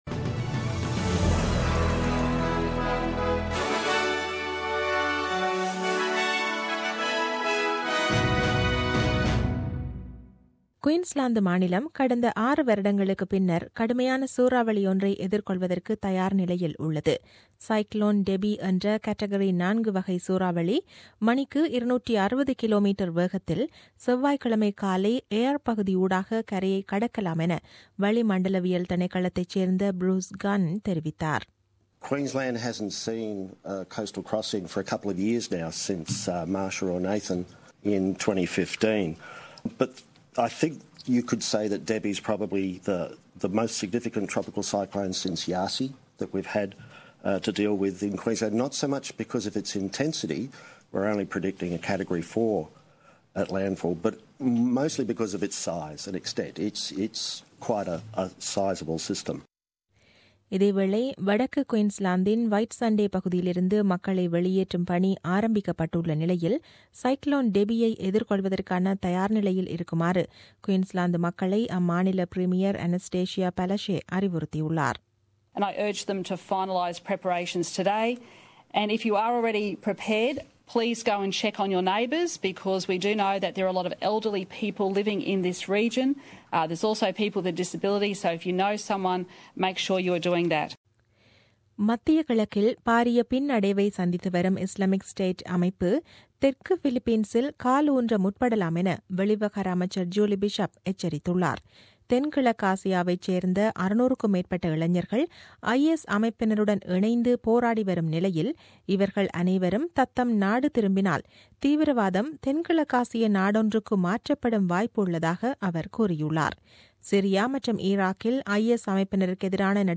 The news bulletin aired on 26 March 2017 at 8pm.